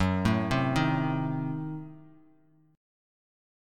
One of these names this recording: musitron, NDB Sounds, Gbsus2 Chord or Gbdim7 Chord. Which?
Gbdim7 Chord